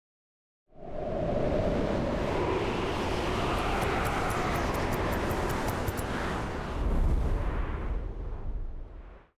Babushka / audio / sfx / Battle / Vesna / SFX_Schlappentornado_04.wav
SFX_Schlappentornado_04.wav